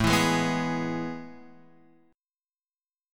AM7sus2 chord {5 7 6 4 x 4} chord